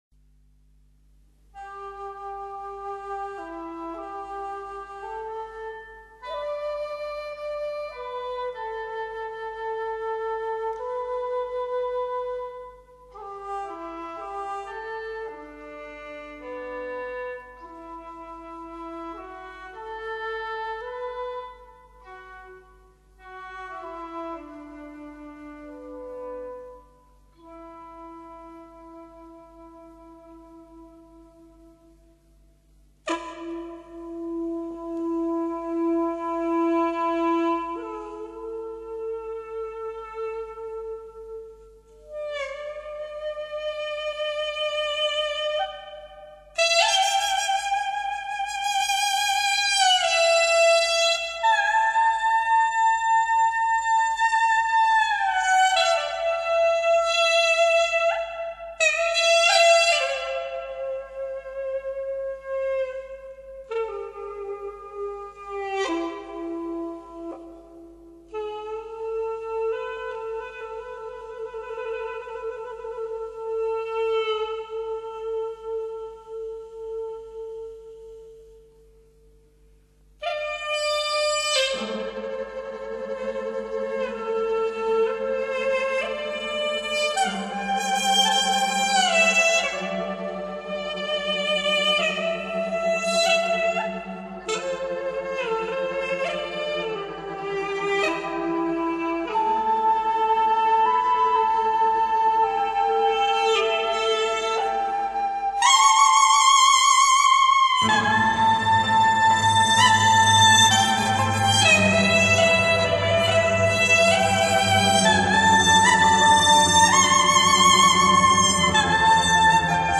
举国共哀!为四川地震中受难的同胞祈福!!竹管乐---江河水 激动社区，陪你一起慢慢变老！